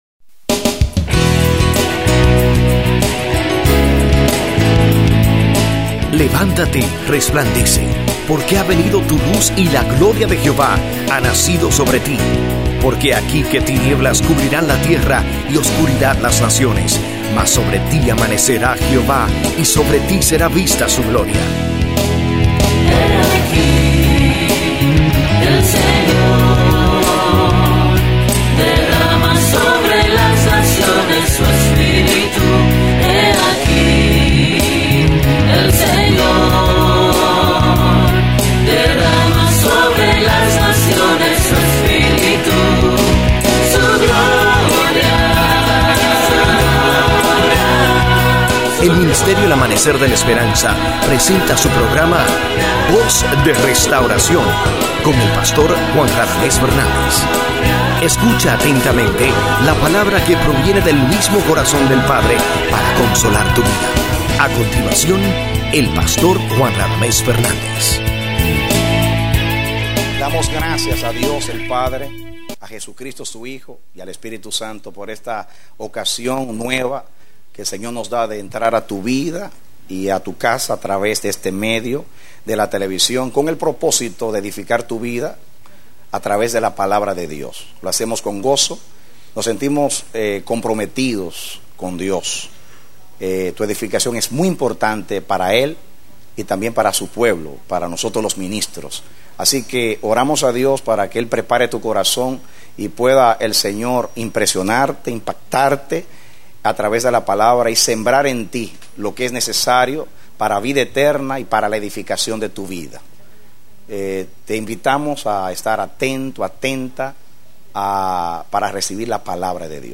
Predicado Mayo 29, 2005